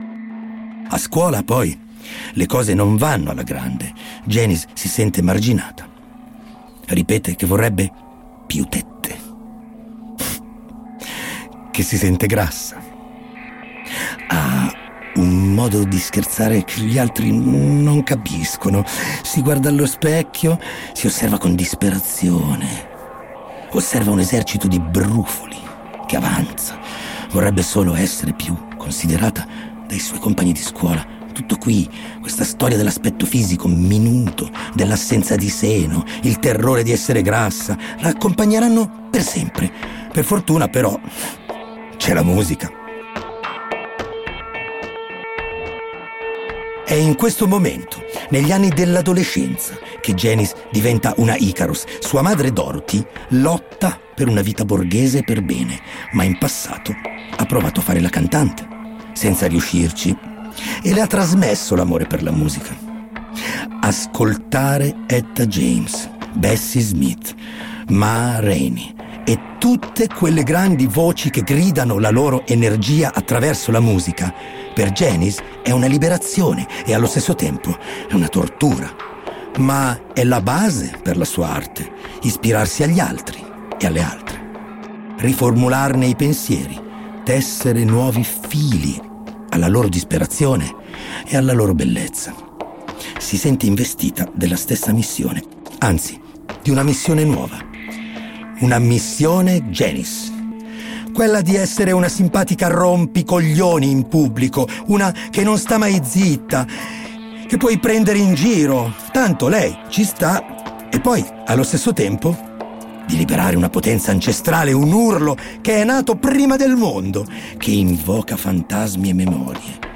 Come ogni settimana un estratto del podcast che racconta la vita di artisti che se ne sono andati troppo in fretta.
Nel podcast Spotify Originals, prodotto da Gli Ascoltabili ed Operà Music, attraverso la voce di Morgan, verrà tracciata in modo originale questa storia, che come le altre si è consumata troppo presto.